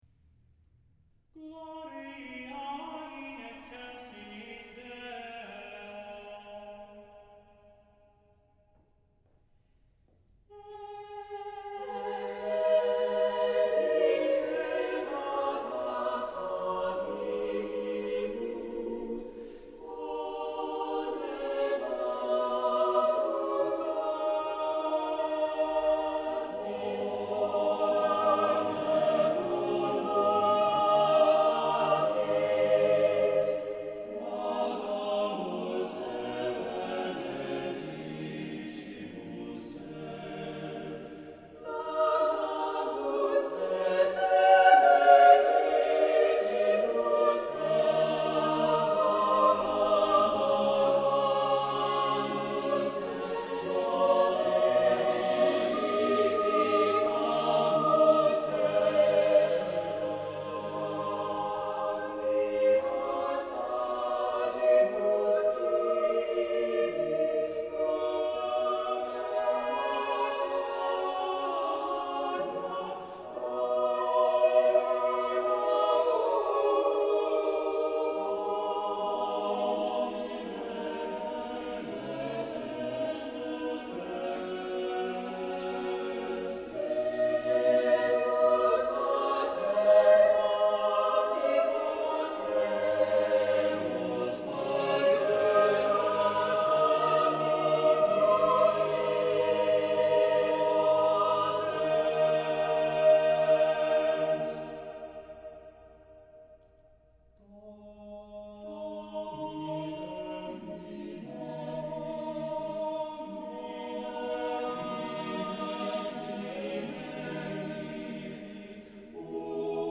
Vocal Ensemble